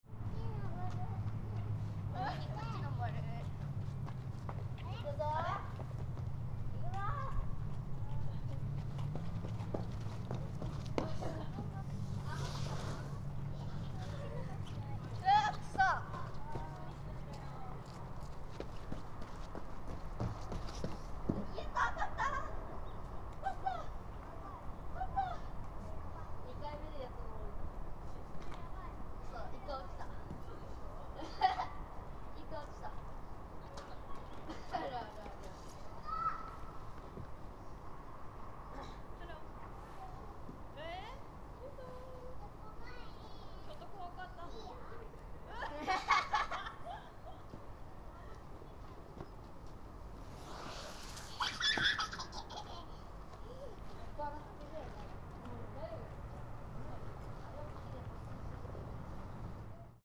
In unseasonably warm weather for Febrary, some families were playing in the park. ♦ Cawing of crows and twittering of birds could be heard, but they were not so frequent.